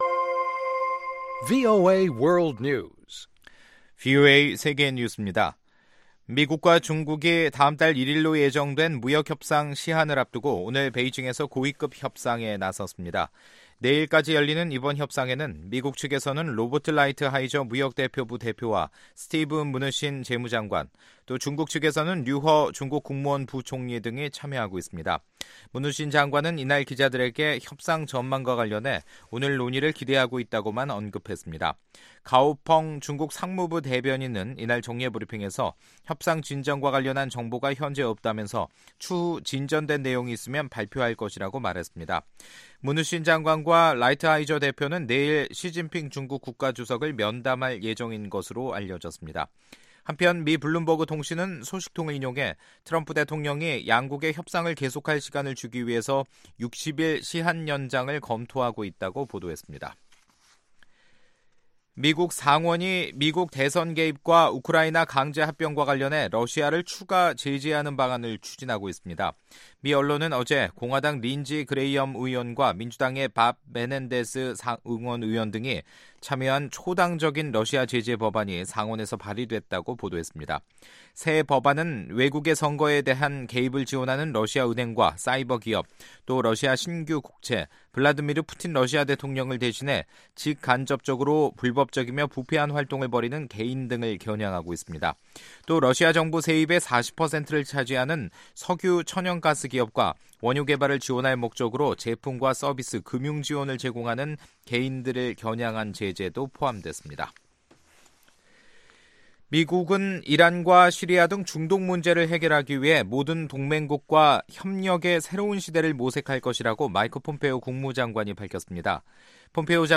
VOA 한국어 간판 뉴스 프로그램 '뉴스 투데이', 2019년 2월 14일 2부 방송입니다. 미 국무부는 북한의 비핵화에 대해 전례 없는 보상을 할 것이라는 점을 거듭 약속했습니다. 미 하원 외교위원장은 미국을 방문한 한국 국회 대표들에게 김정은 위원장과 벌이는 협상의 위험성과 미-한 동맹의 중요성을 전했다고 말했습니다.